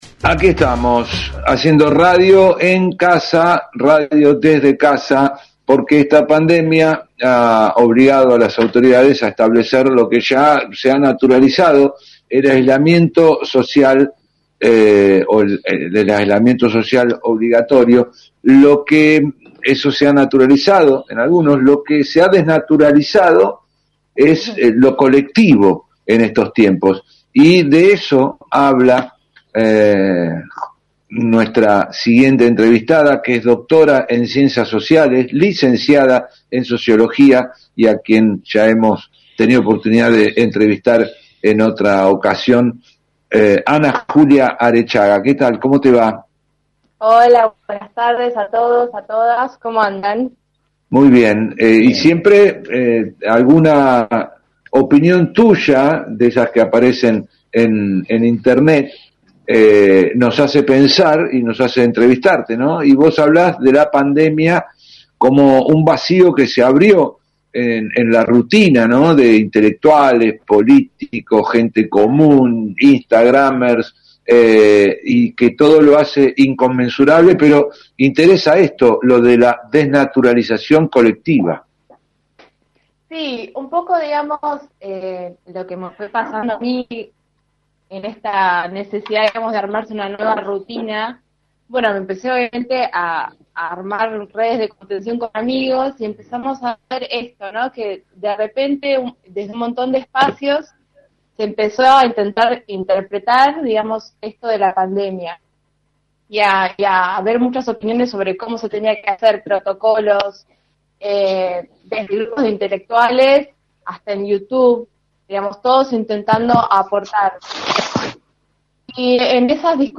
esta charla con radio Universidad